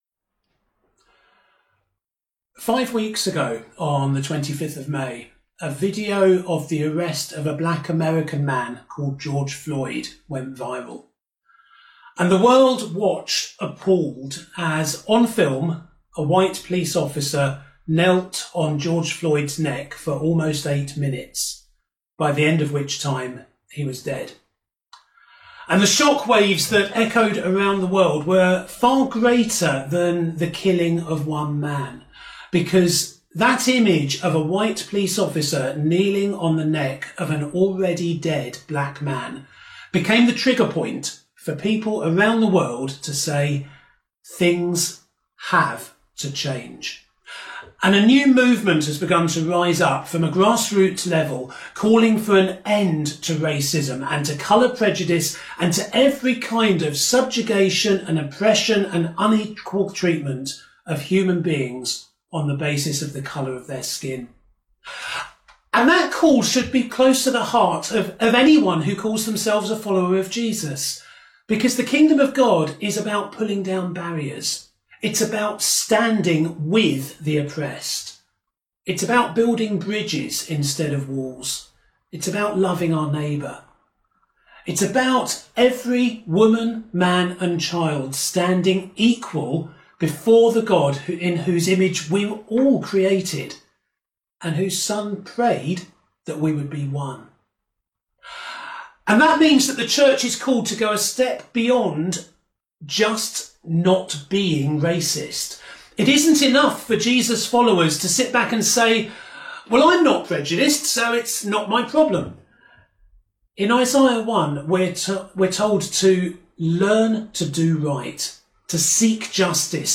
July 5th sermon.mp3